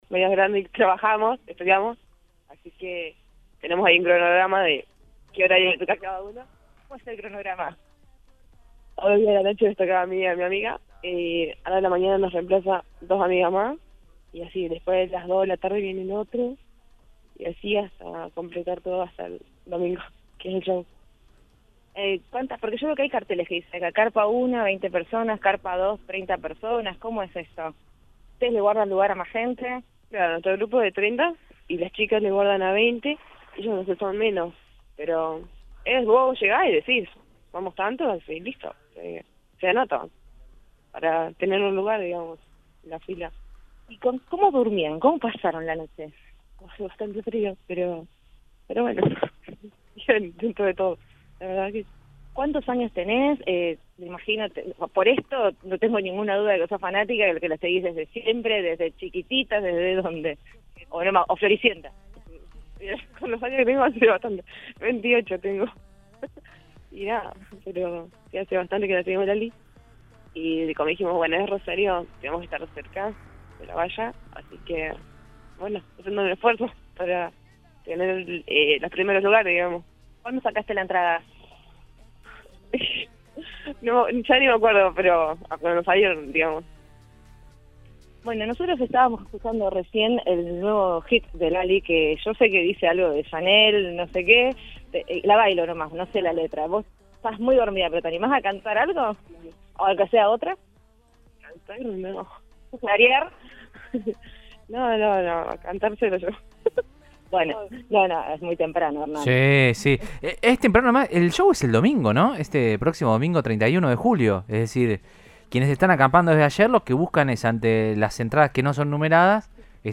Hay chicas que están desde ayer a la tarde acampando. Trabajamos, estudiamos, así que tenemos un cronograma para definir a qué hora viene cada uno”, explicó una fanática al móvil de Cadena 3 Rosario, en Radioinforme 3.